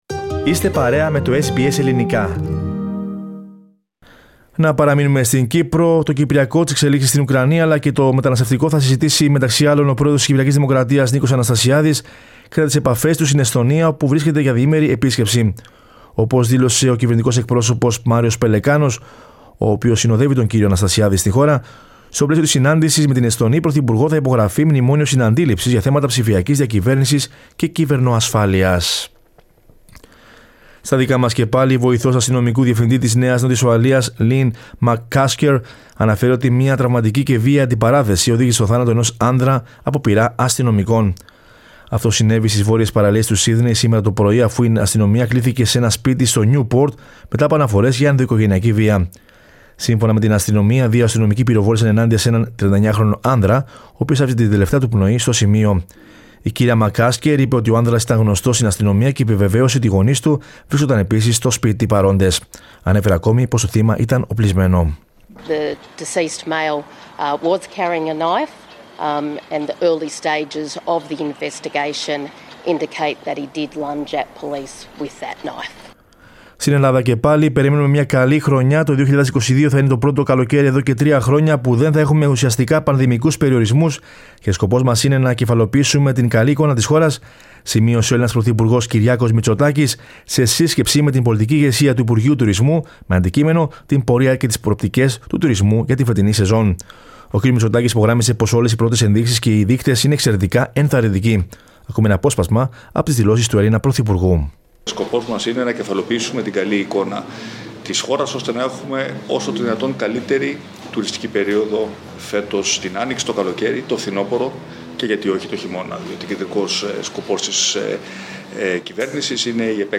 Δελτίο Ειδήσεων Πέμπτης 28.04.22